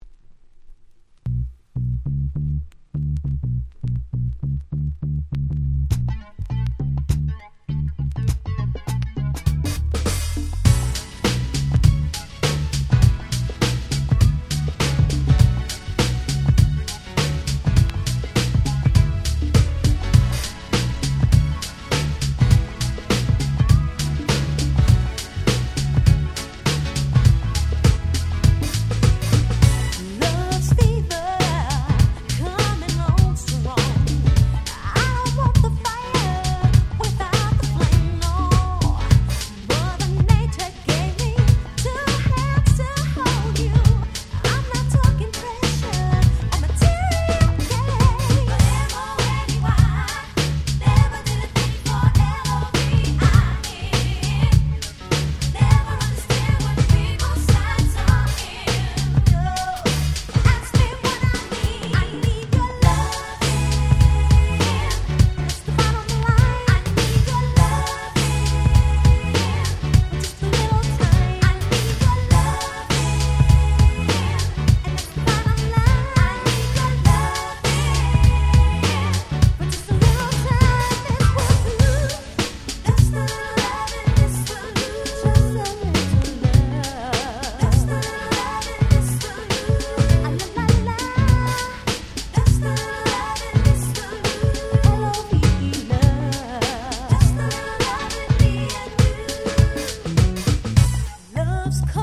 96' Very Nice R&B / New Jack Swing / Hip Hop Soul !!
90's NJS ニュージャックスウィング ハネ系